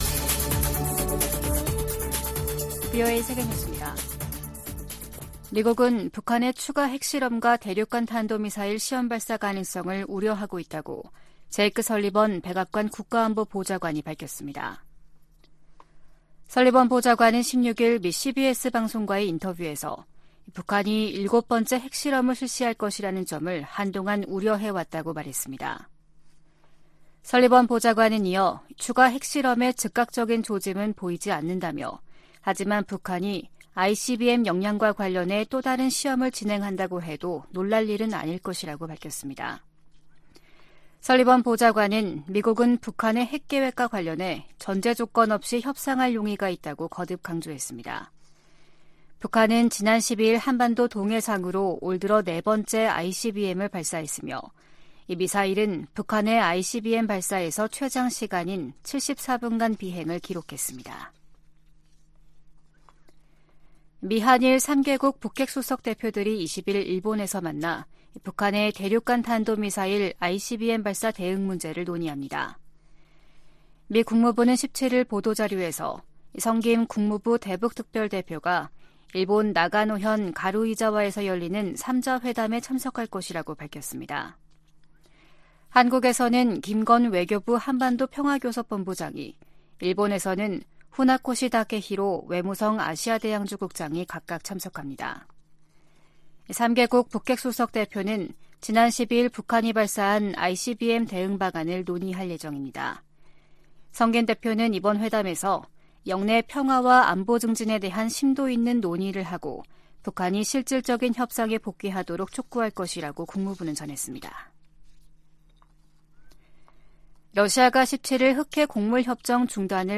VOA 한국어 아침 뉴스 프로그램 '워싱턴 뉴스 광장' 2023년 7월 18일 방송입니다. 김여정 북한 노동당 부부장이 담화를 내고 대륙간탄도미사일 '화성-18형' 발사의 정당성을 주장하면서 미국을 위협했습니다. 인도네시아 자카르타에서 열린 제30차 아세안지역안보포럼(ARF) 외교장관회의에서 미한일 등 여러 나라가 북한의 탄도미사일 발사를 규탄했습니다. 미 상원에서 한국 등 동맹국의 방위비 분담 내역 의회 보고 의무화 방안이 추진되고 있습니다.